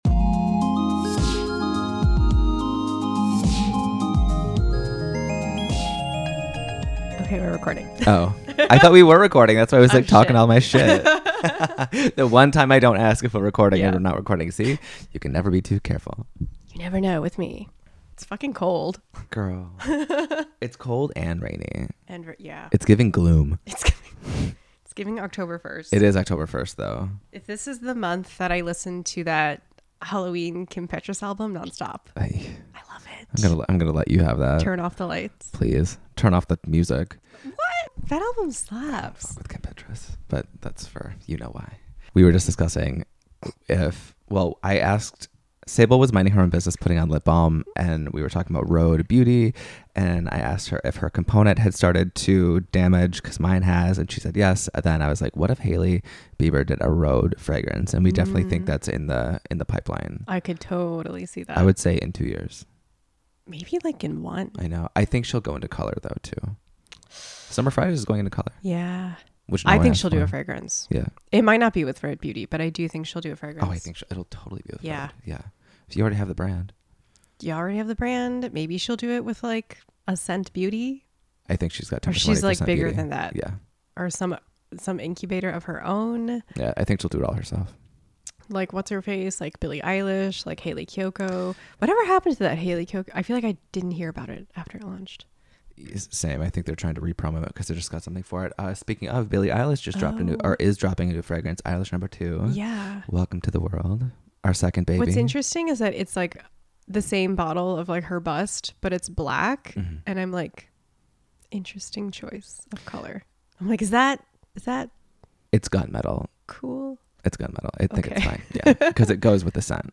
As this episode title suggests, this is another classic chat, featuring listener voice memo guidance, new smells, October vibes (?), seasonal scents not being a thing except for fall (and maybe summer), scents we want to smell but haven't yet, etc. Surprisingly!